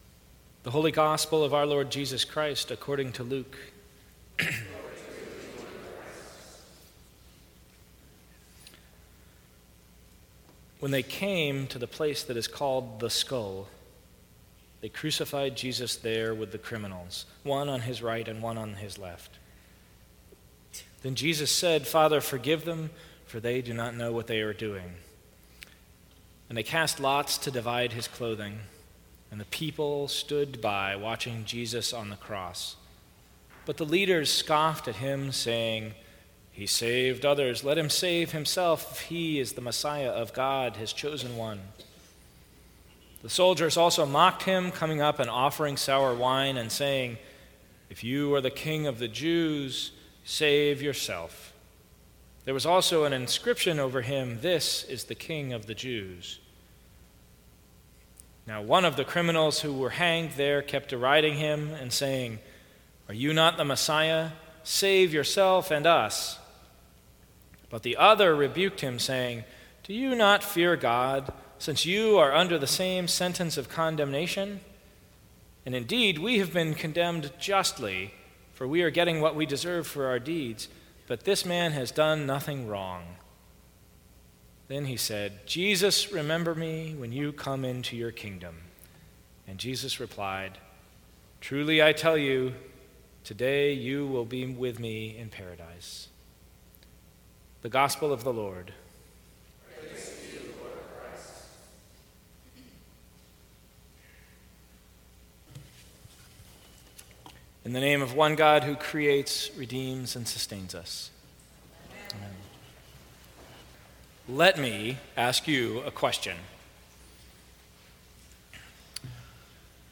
Sermons from St. Cross Episcopal Church Christ The King Sunday Nov 21 2016 | 00:17:07 1x 00:00 / 00:17:07 Subscribe Share Apple Podcasts Spotify Overcast RSS Feed Share Link Embed